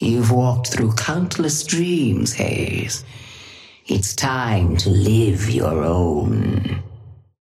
Sapphire Flame voice line - You've walked through countless dreams, Haze. It's time to live your own.
Patron_female_ally_haze_start_03.mp3